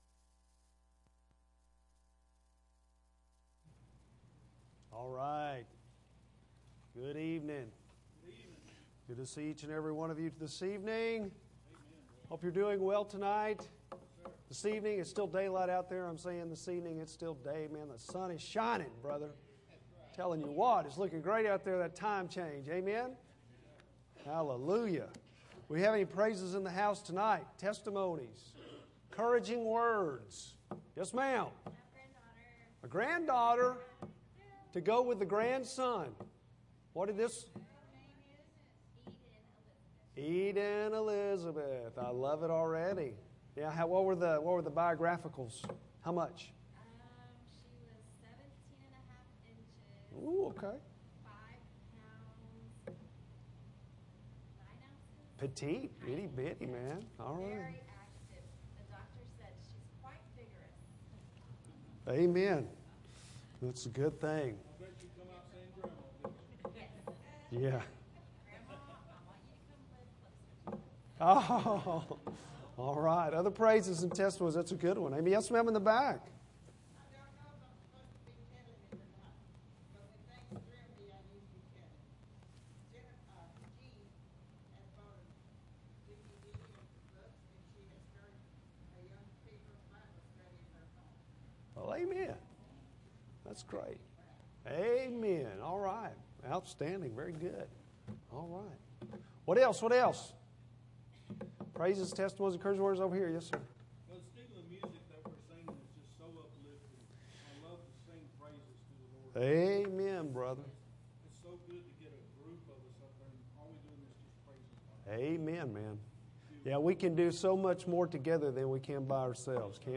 Bible Text: Mark 11:15-26 | Preacher